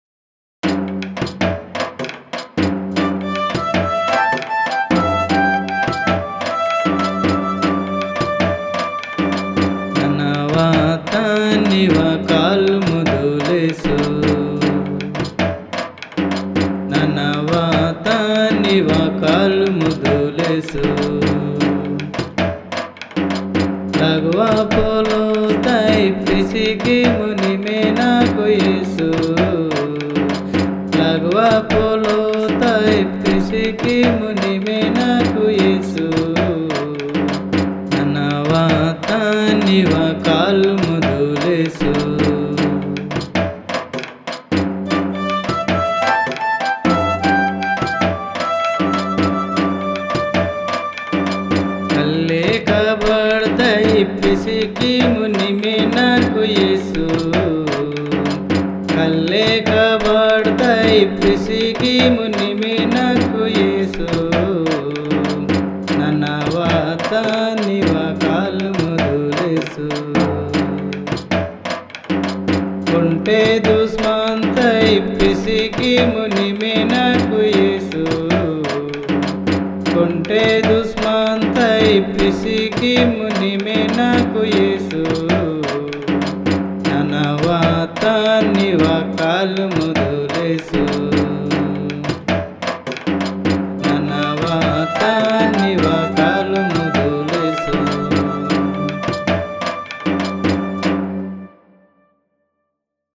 Madya_Gospel_Song.mp3